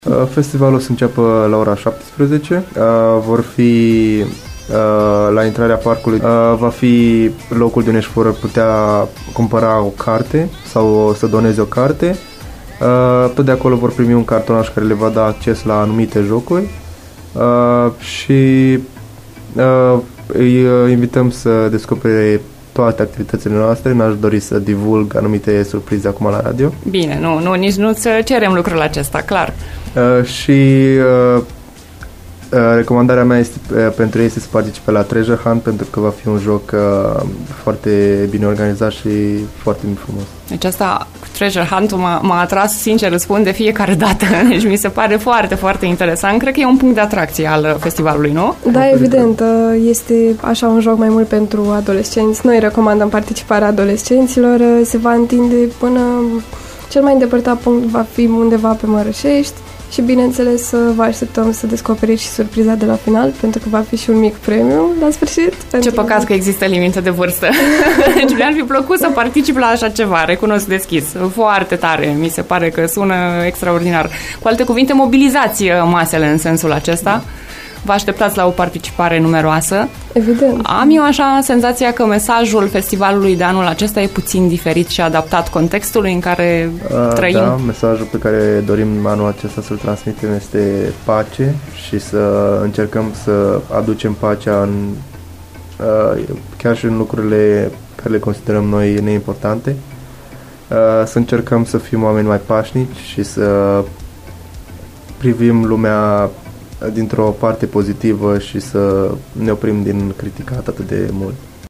Festivalul luminii și al păcii, live la „After Morning”